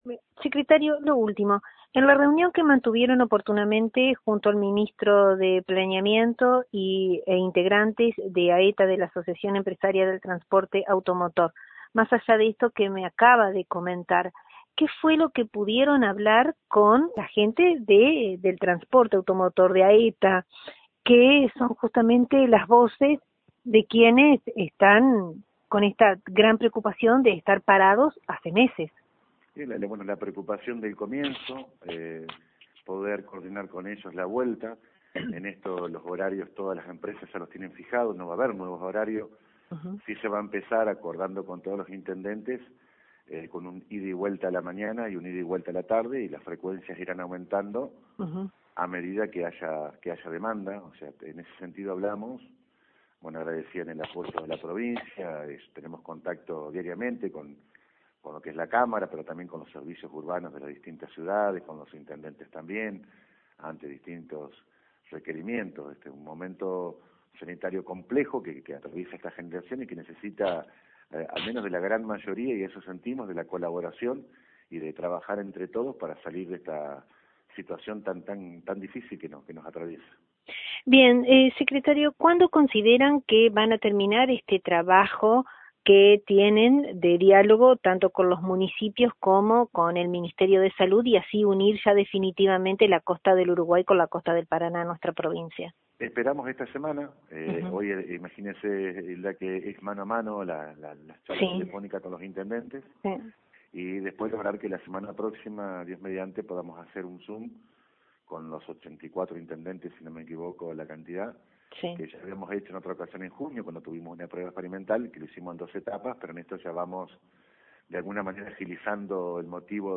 Reapertura de viajes interurbanos micros & trenes: habló con LT39 NOTICIAS el Secretario Provincial de Transporte Néstor Landra